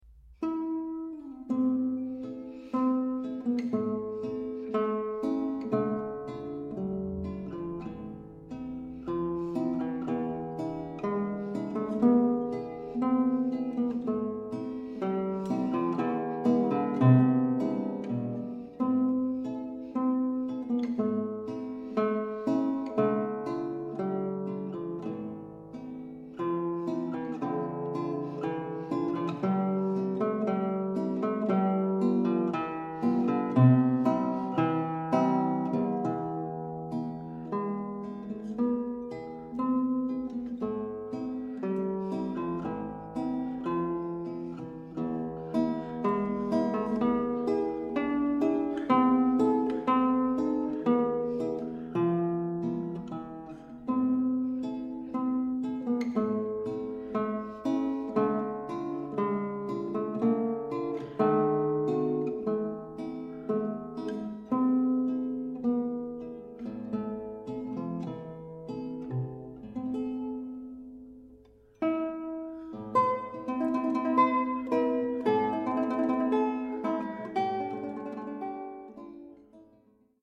Skladby pro osmistrunnou kytaru
"Dřevěný kostelík", Blansko 2010